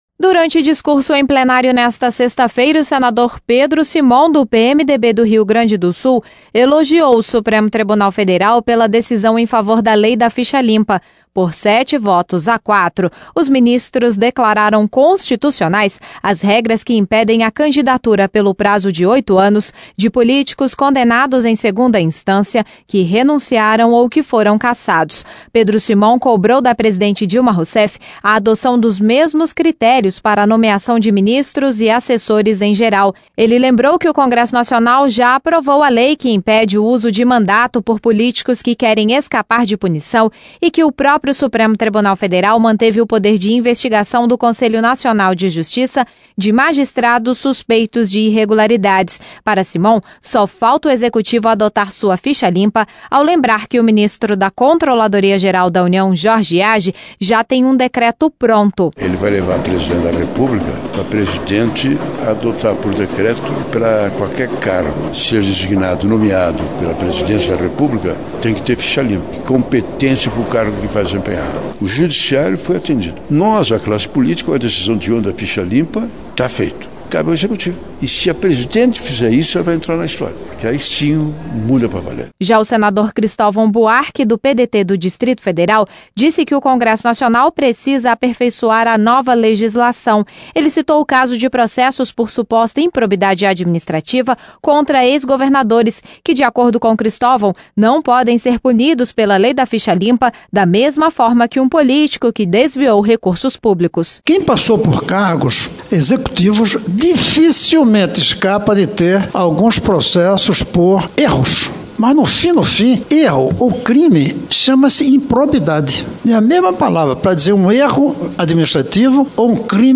TÉC: Durante discurso em Plenário nesta sexta-feira, o senador Pedro Simon do PMDB do Rio Grande do Sul elogiou o Supremo Tribunal Federal pela decisão em favor da Lei da Ficha Limpa.